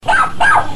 dog 12 mp3
dog_12.mp3